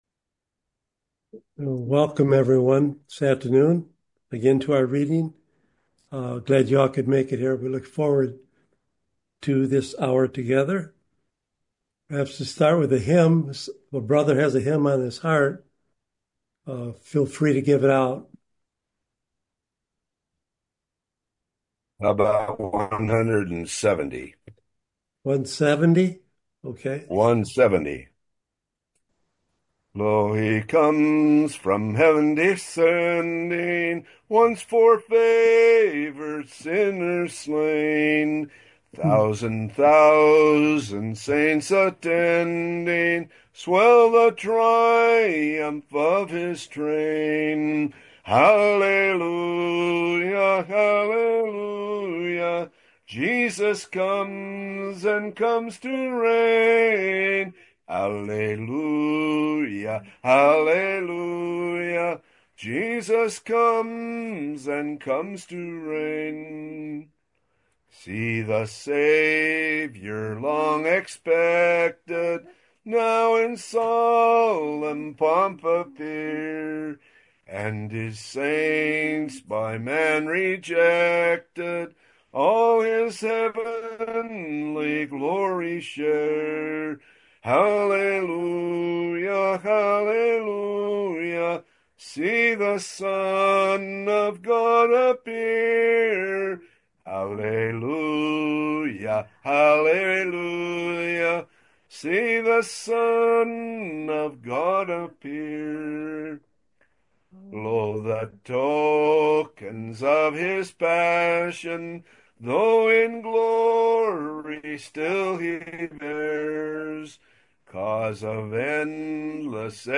Recording from Sunday Afternoon Reading.